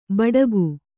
♪ baḍagu